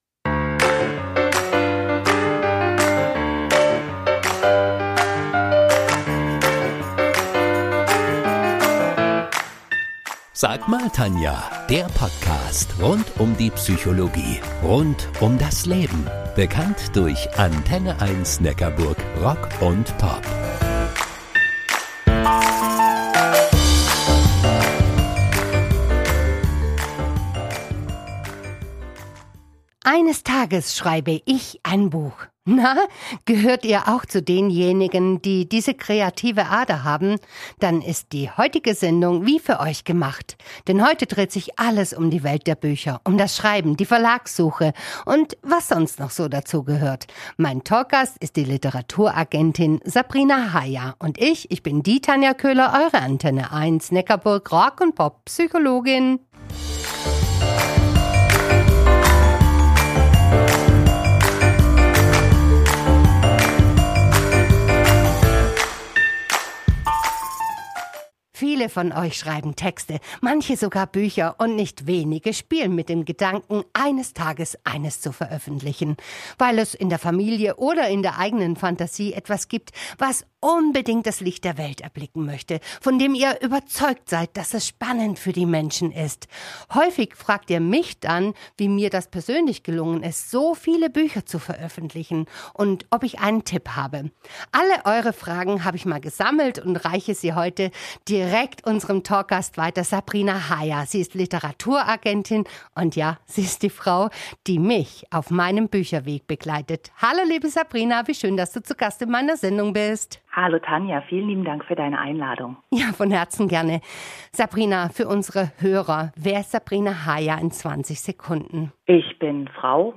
Im Gespräch mit der Literaturagentin